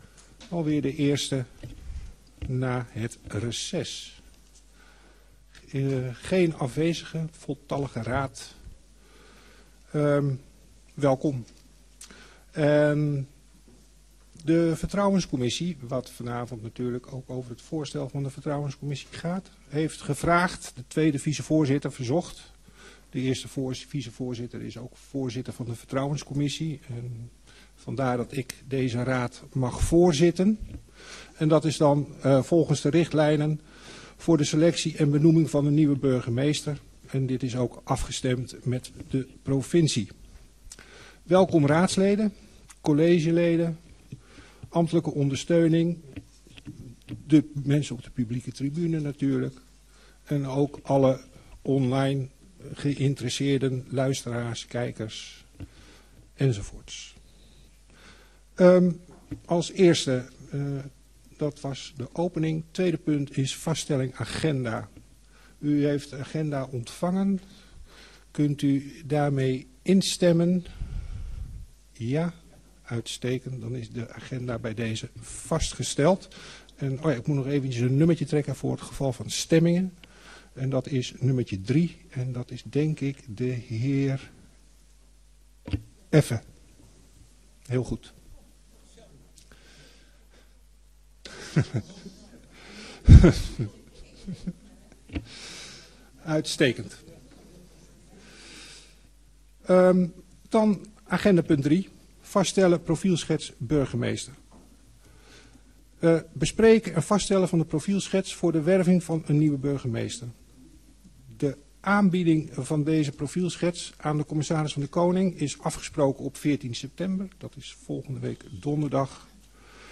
Gemeenteraad 07 september 2023 20:00:00, Gemeente Heemstede
Download de volledige audio van deze vergadering